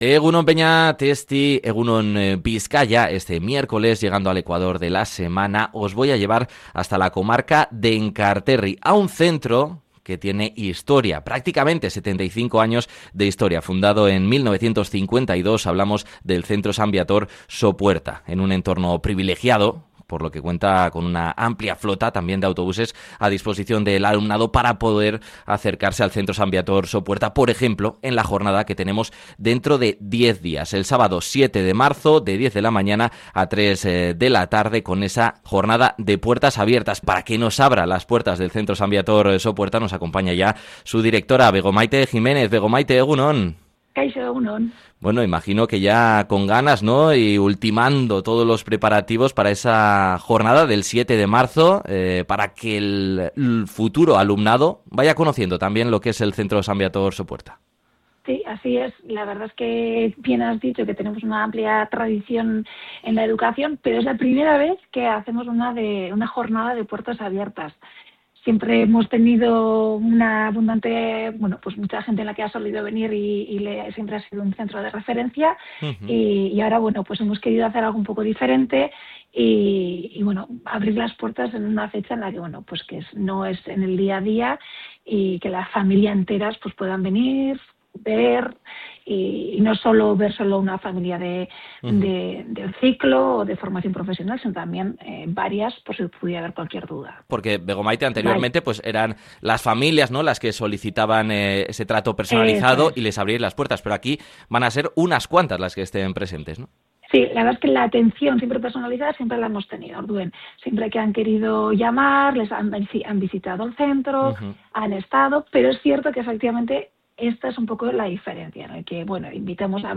Durante la entrevista en “EgunOn Bizkaia”